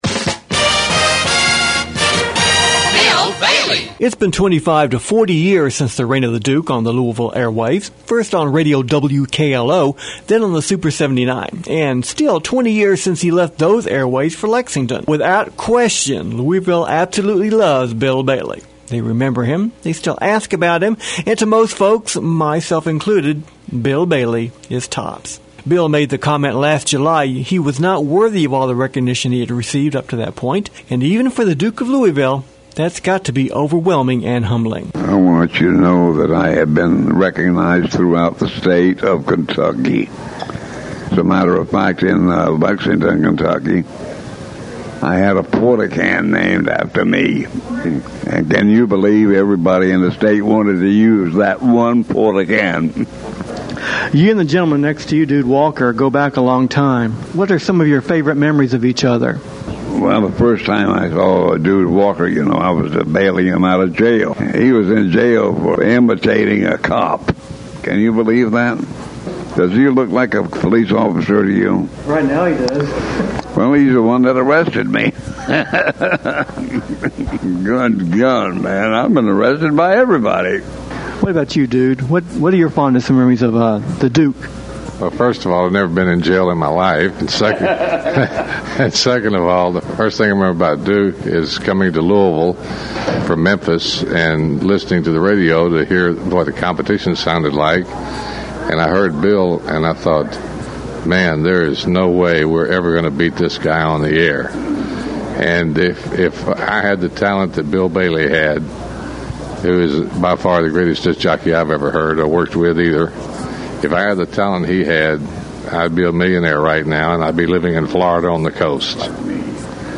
However, in the mixing and editing process, some parts were omitted from the interview.